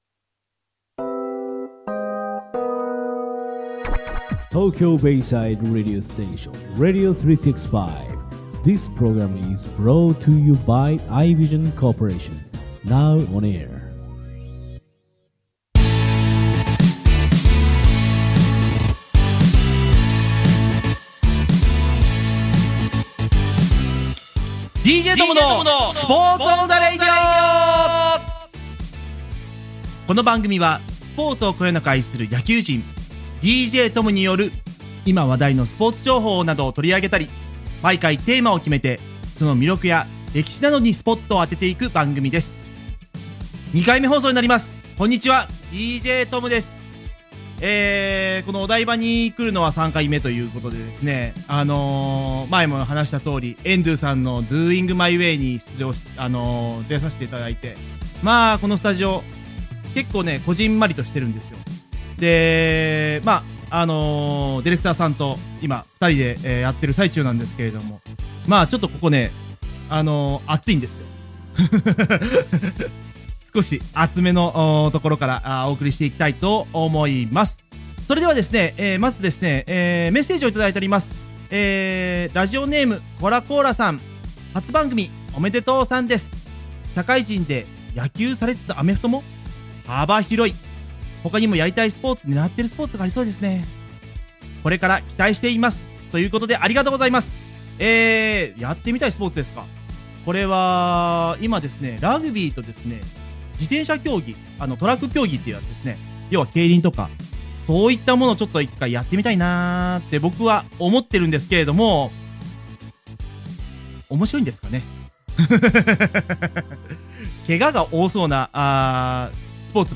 初回放送よりはカミカミモードが減ったとは思いますが、どうでしょうか？